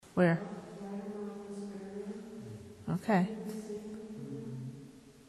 As we are seated in the church, someone asks about seeing small points of light. As we are talking a low moan is heard twice.